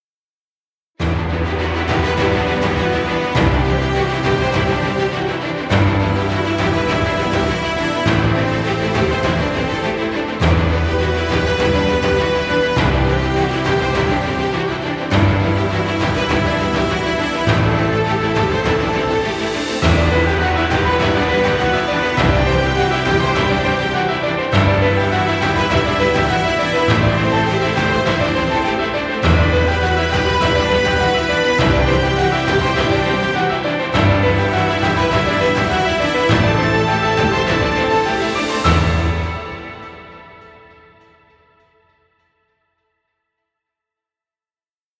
Epic dramatic heroic music.